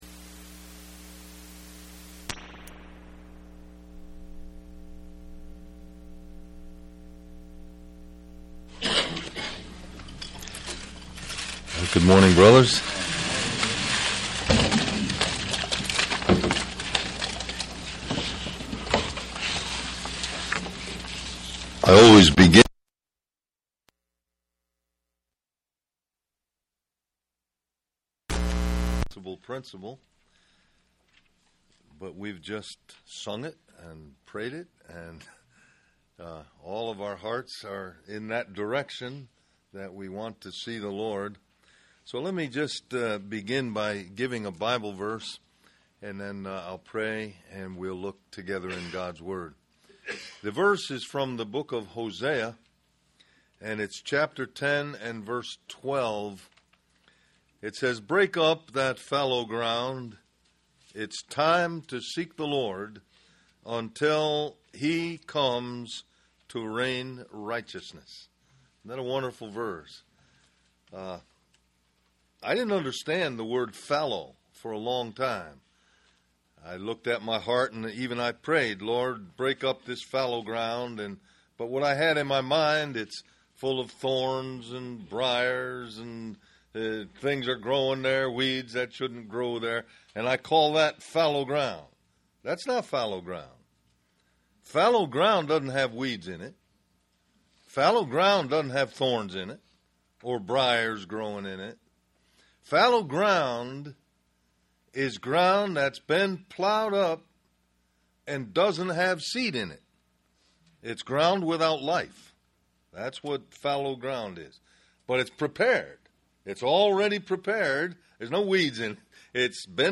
Del-Mar-Va Men's Retreat